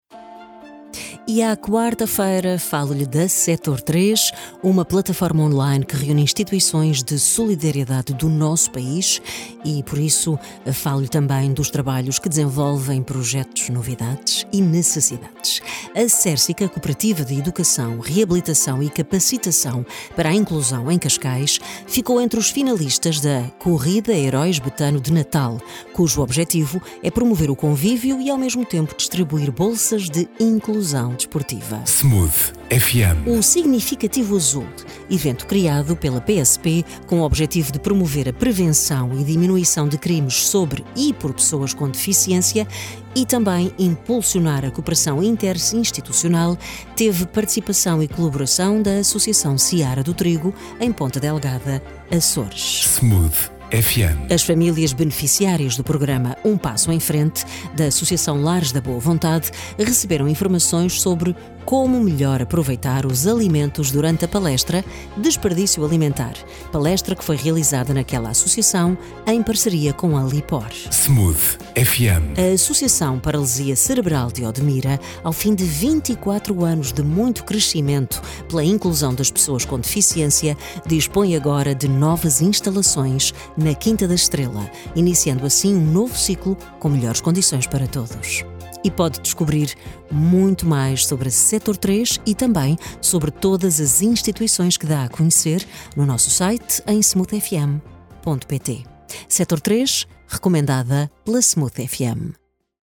10 dezembro 2025 Flash Smooth FM CERCICA | Associação Seara do Trigo | Lares da Boa Vontade | APCO partilhar Facebook Twitter Email Apontamento rádio sobre a atividade desenvolvida por entidades-membros do Diretório Sector 3, que vai para o ar todas as quartas-feiras, às 8h, às 13h e às 17h.